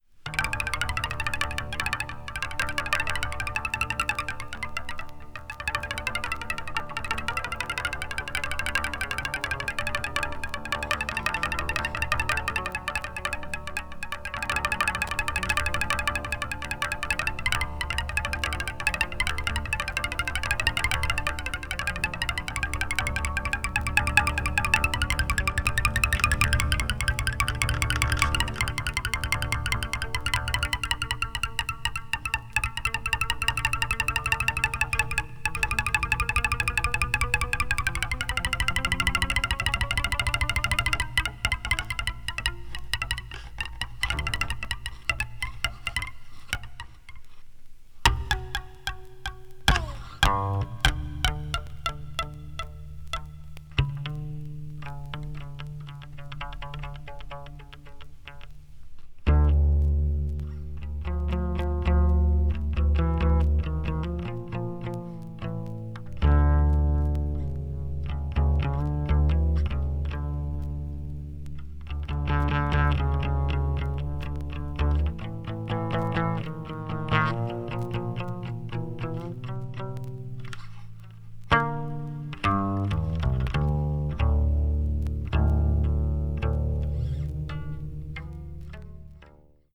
media : EX-/EX-(わずかなチリノイズが入る箇所あり,軽いプチノイズ数回あり)